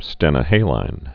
(stĕnə-hālīn, -hălīn)